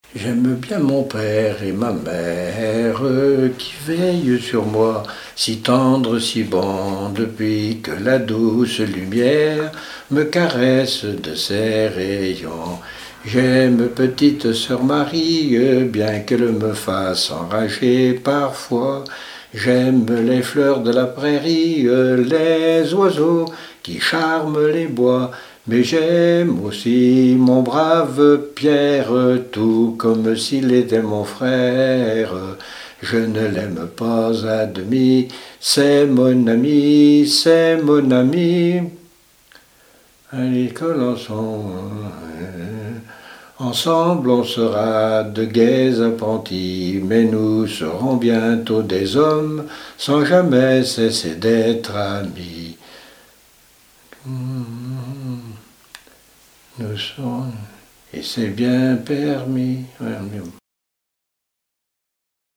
Genre brève
Répertoire de chants brefs pour la danse
Pièce musicale inédite